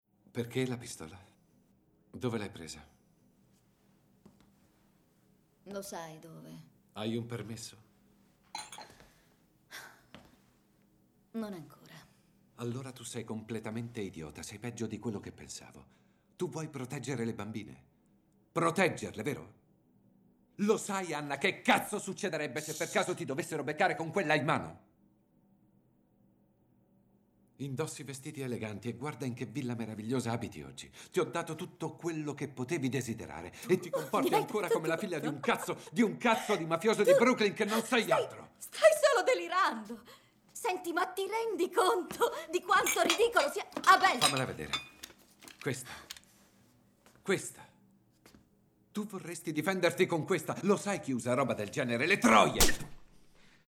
vocemvir.mp3